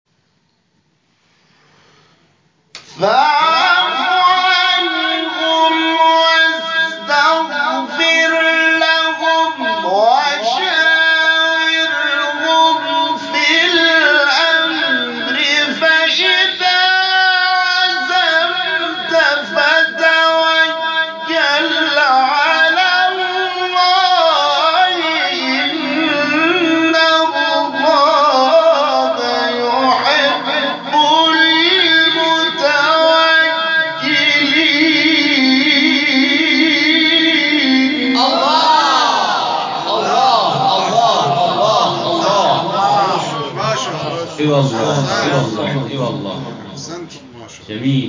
گروه شبکه اجتماعی: نغمات صوتی از تلاوت‌های قاریان به‌نام کشور را می‌شنوید.